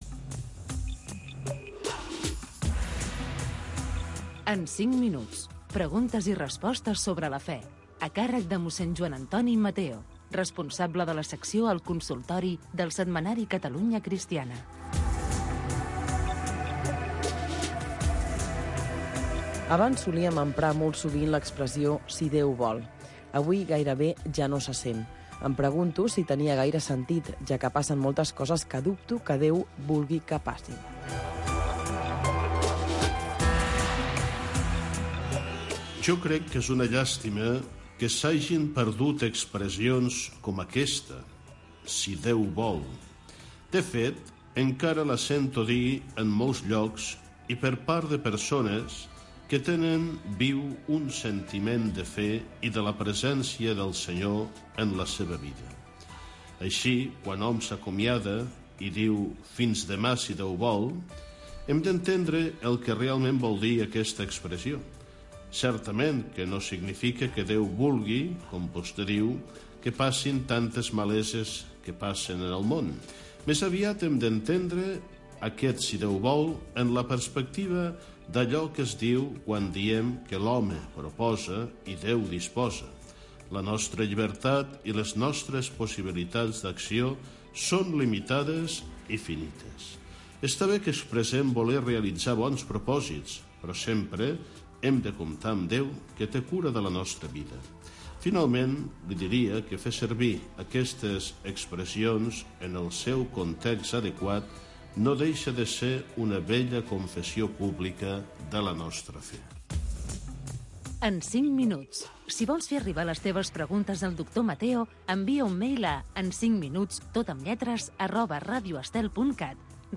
Espai on els oients poden fer consultes de tipus religiós